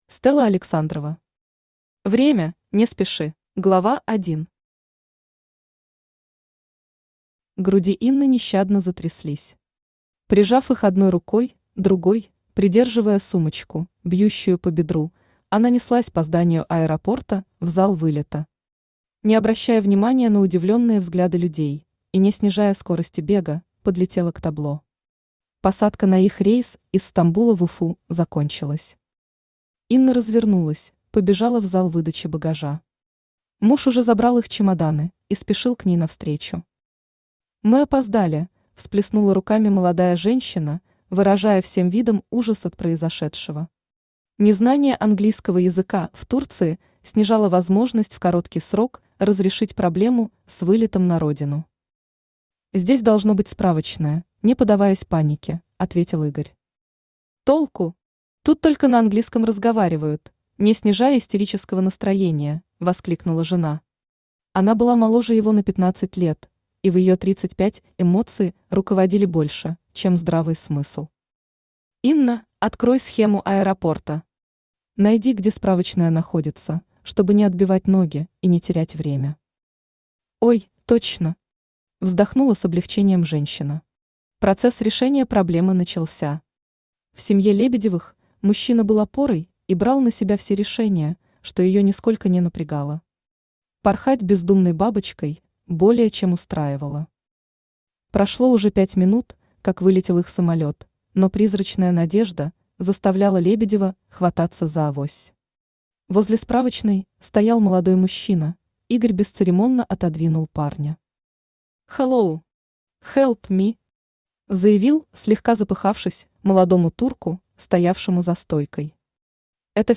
Аудиокнига Время, не спеши | Библиотека аудиокниг
Aудиокнига Время, не спеши Автор Стелла Александрова Читает аудиокнигу Авточтец ЛитРес.